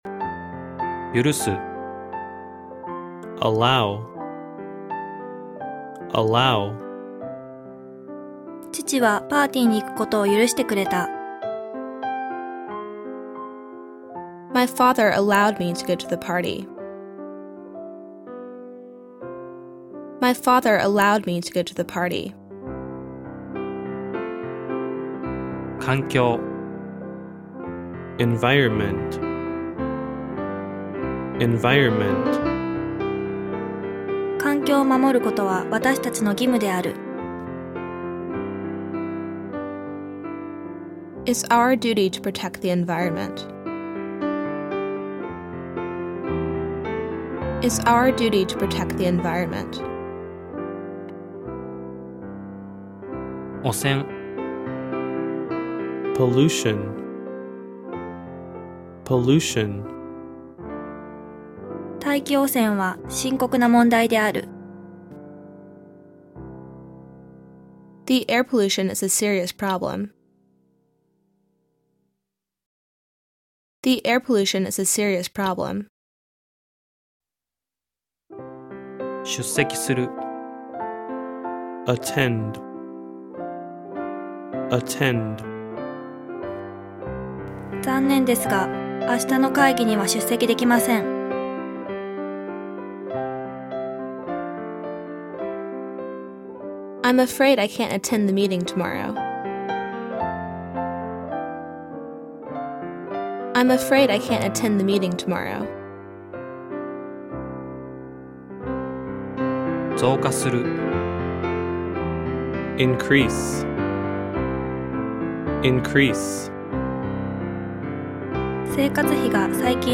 日本語ナレーションから考えた後、ネイティブ英語を聞く・まねる・話すアウトプットを通して英語が深く定着。BGMに乗ってまねて話せすので、ネイティブのような発音が自然と身につきます。
※英語・ピアノBGMとも高品質録音につき、bluetoothで聞くと、ネイティブ発音も非常にクリアー！